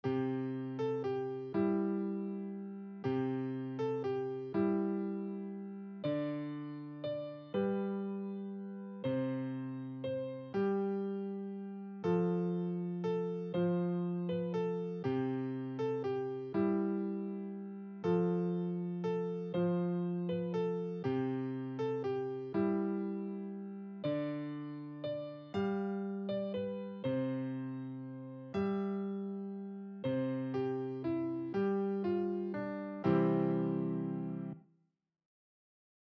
Piano
Douce Nuit - Piano Débutant 120bpm.mp3